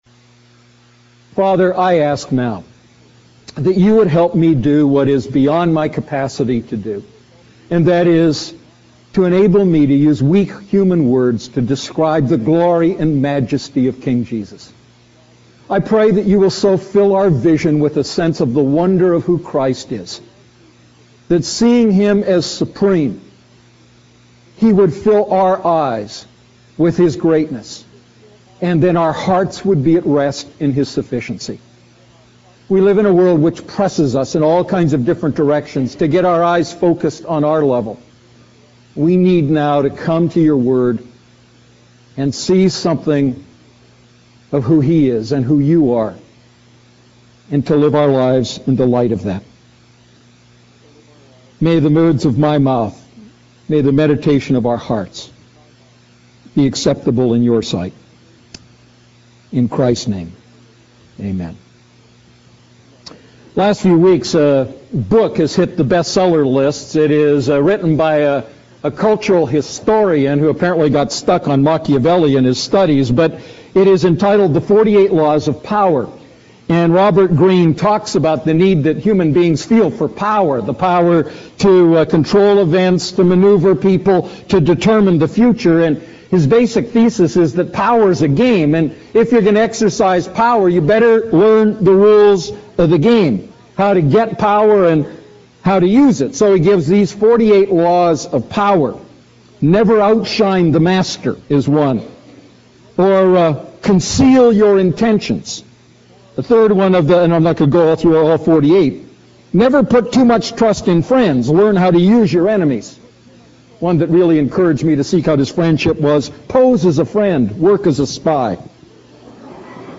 A message from the series "Ephesians Series."